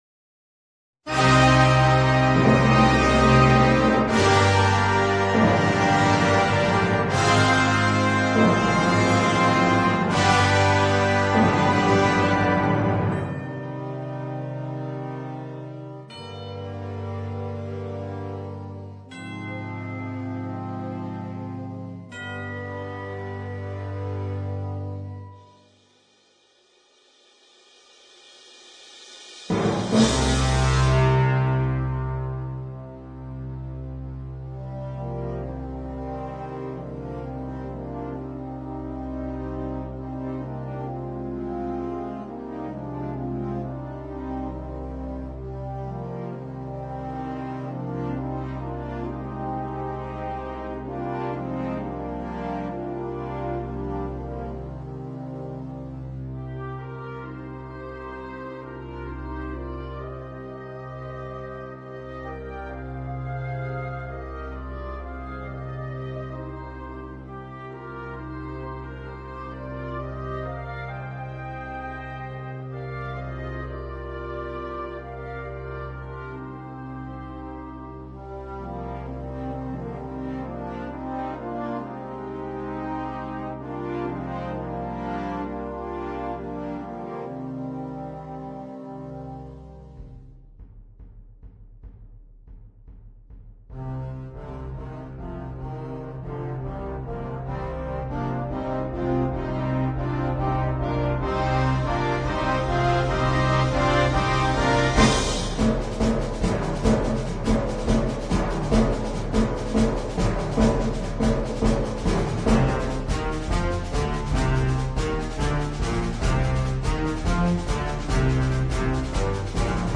poema sinfonico
brani per banda